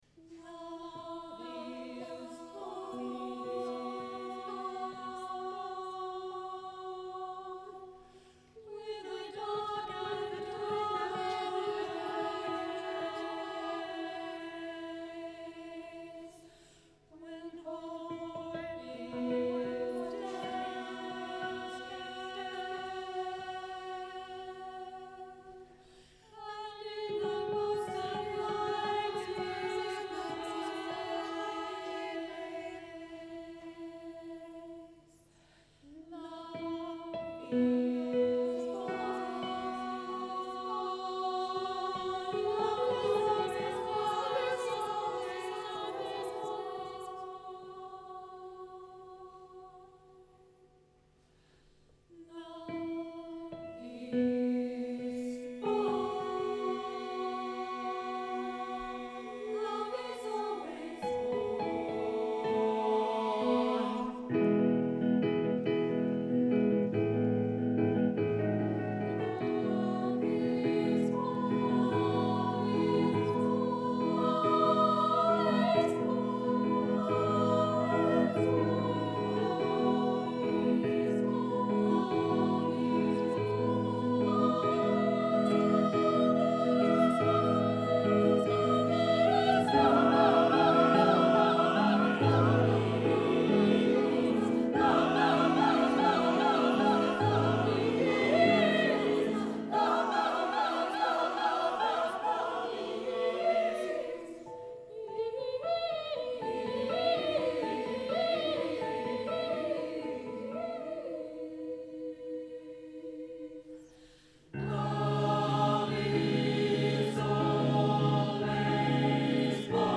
for SSATBarB mixed-voice choir with piano
ecstatic setting
live recording